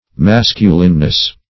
-- Mas"cu*line*ly, adv. -- Mas"cu*line*ness, n.